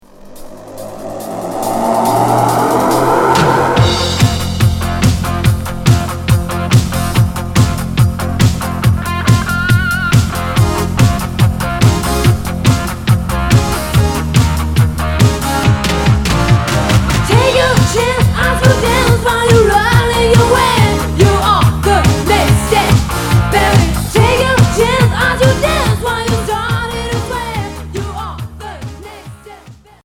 Hard FM Unique 45t retour à l'accueil